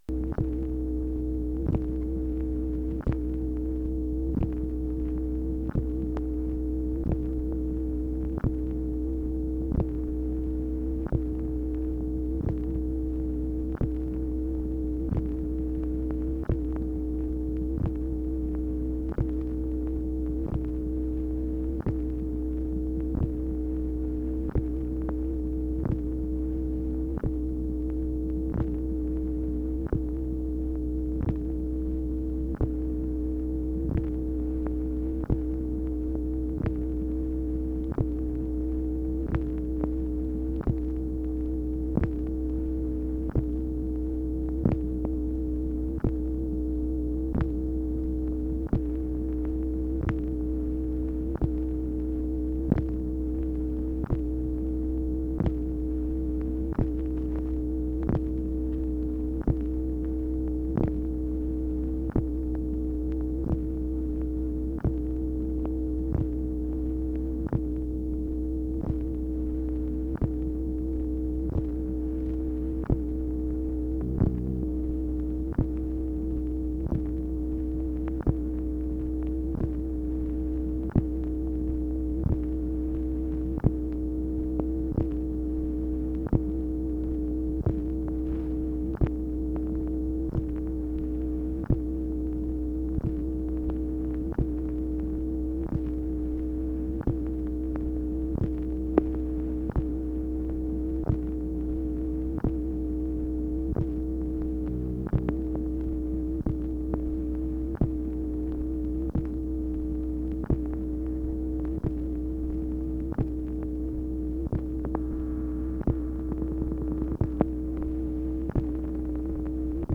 MACHINE NOISE, February 3, 1964
Secret White House Tapes